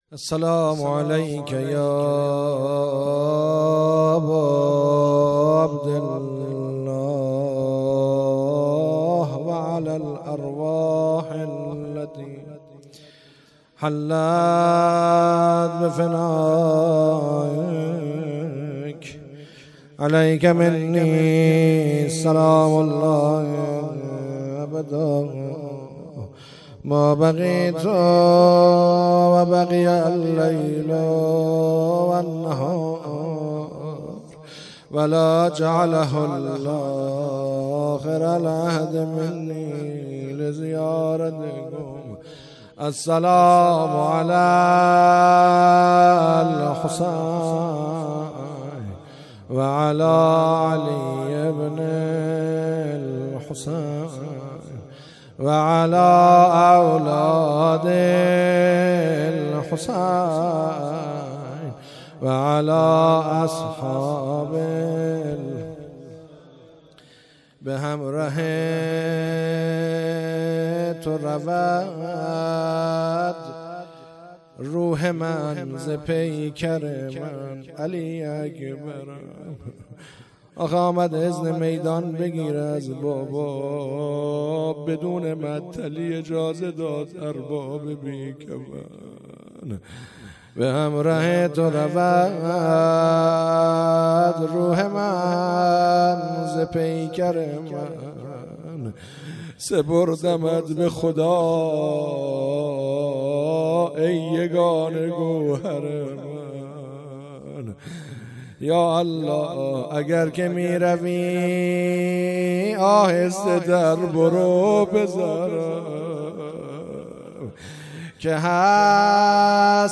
روضه
احیای شب های قدر